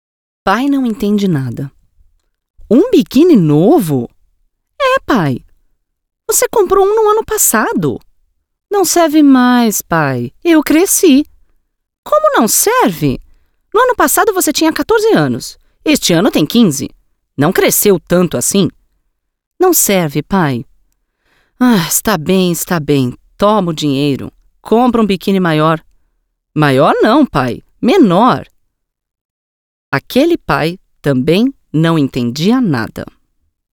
Kräftige, ausdrucksvolle Stimme.
Sprechprobe: Sonstiges (Muttersprache):
Strong, expressive voice.